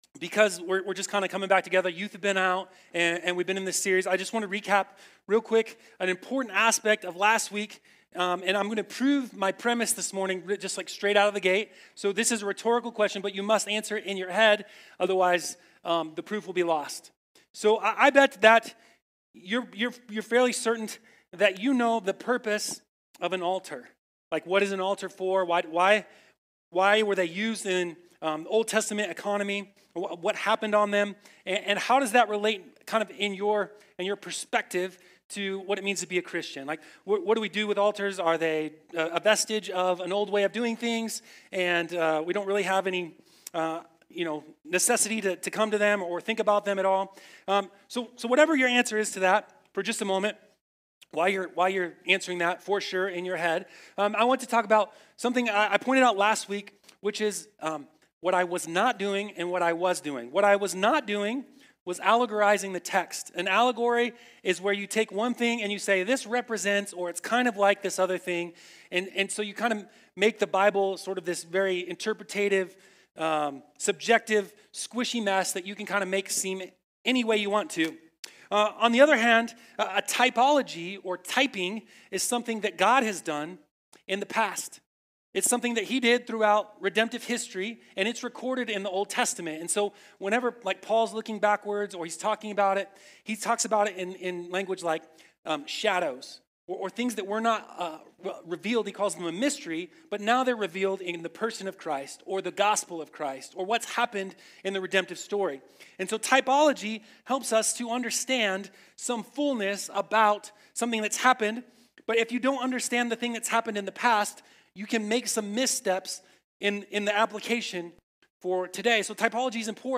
A message from the series "EZRA - Ashes to Glory."